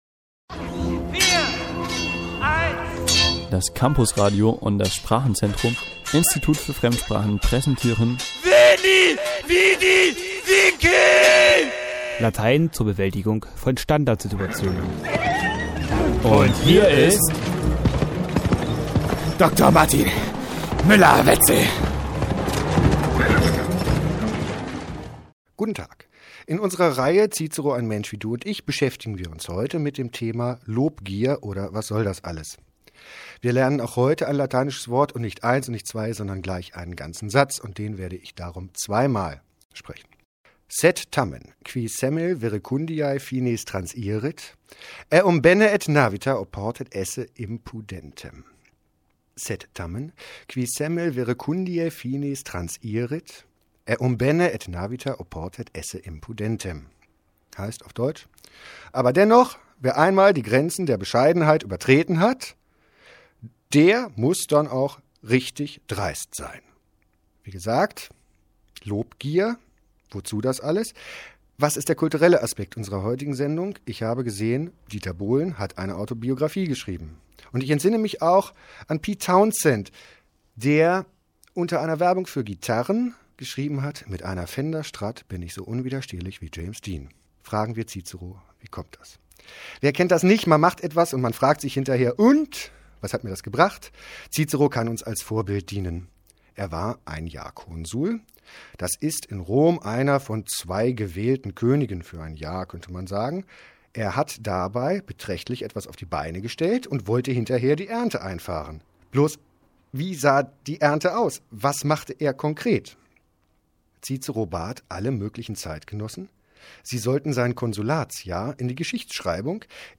Radiolatein-Klassiker aus dem Campusradio als MP3
Achten Sie jeweils auf den Anspann: herrlich, wie das scheppert!